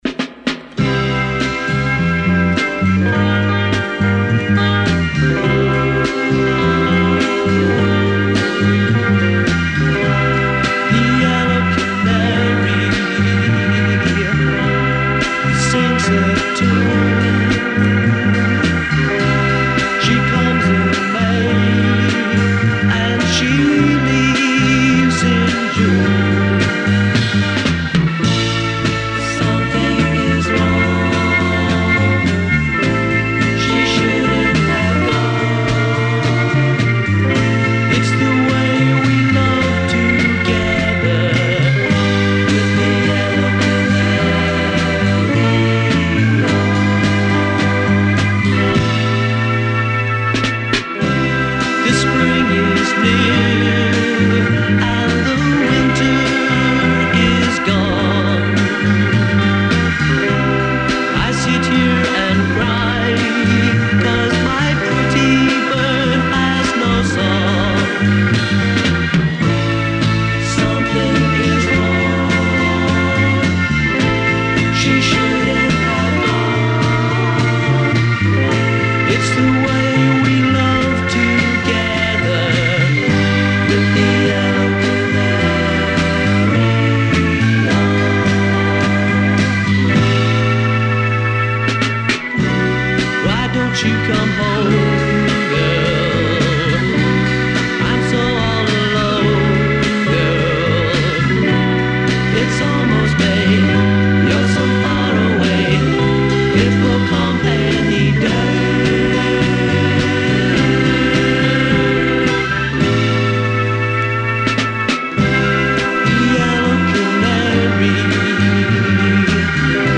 recorded at Lynn Recording Studios in Rochester, MN